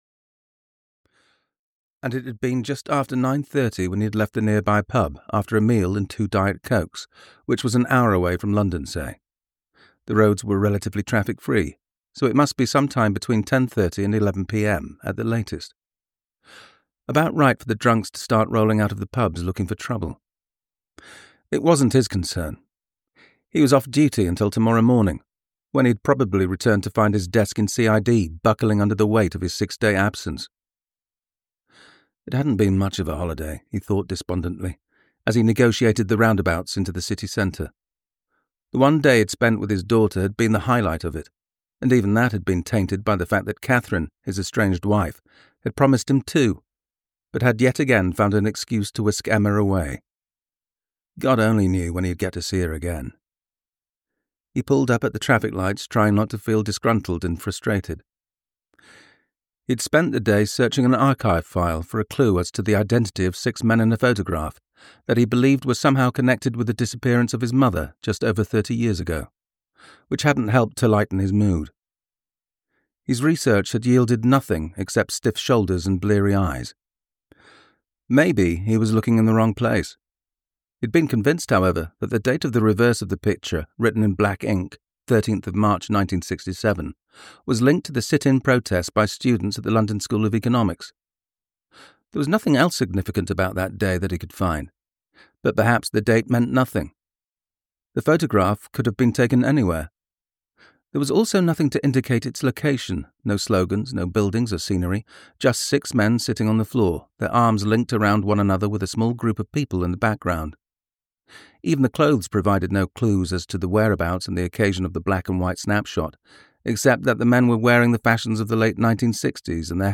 The Oyster Quays Murders (EN) audiokniha
Ukázka z knihy